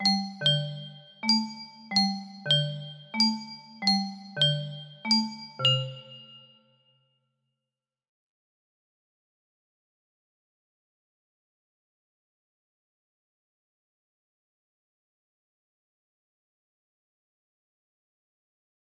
irreality music box melody